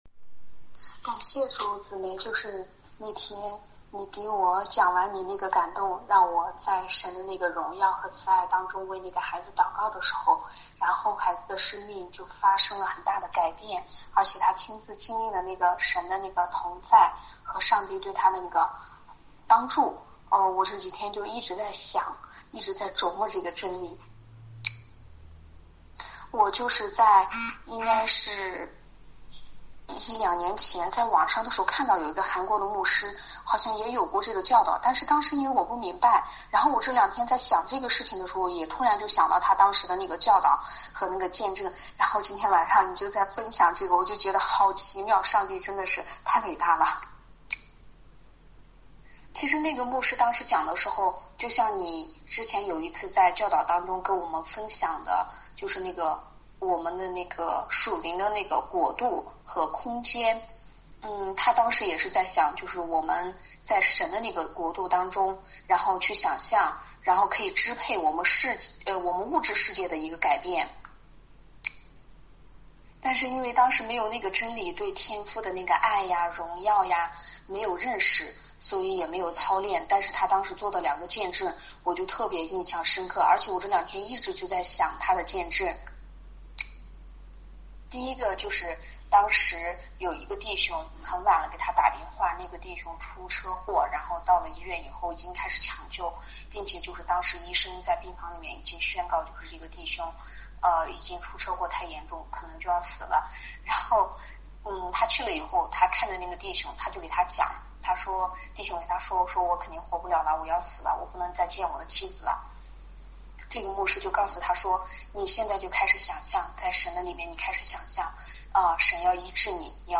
昨晚医治祷告会的后面40分钟的录音，穿插有一些分享。